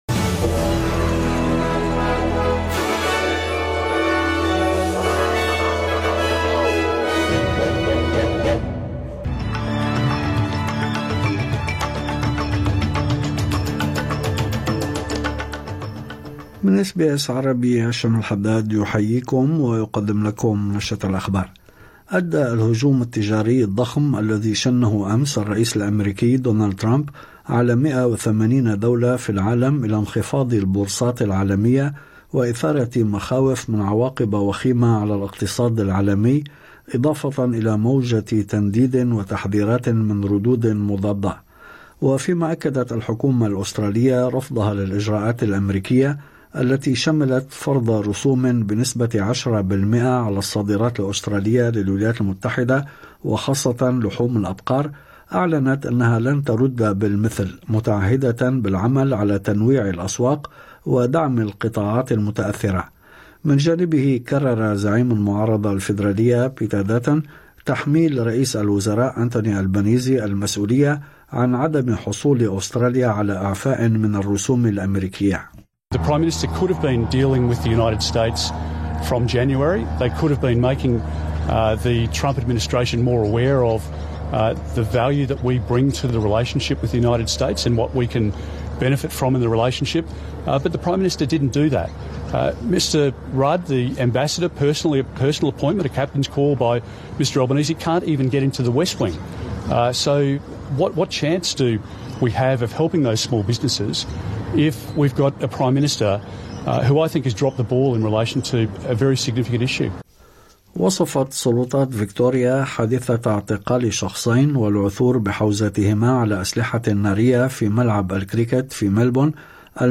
أخبار الظهيرة